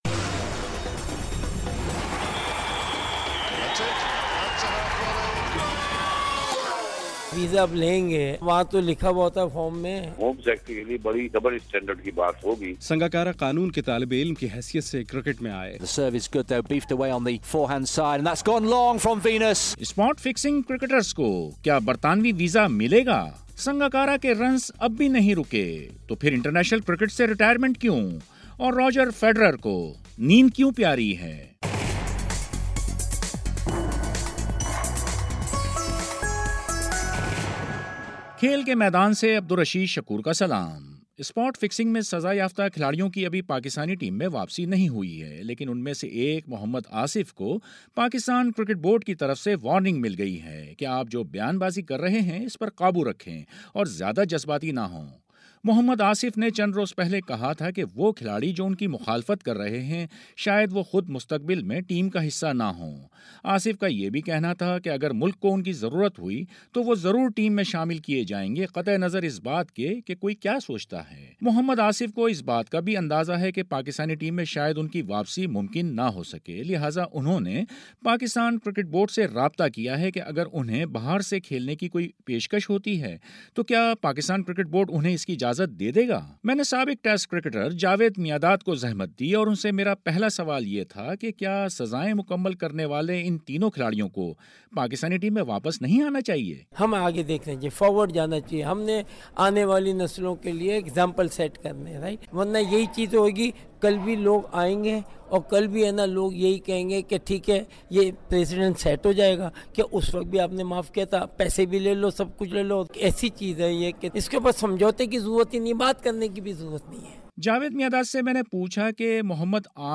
گفتگو۔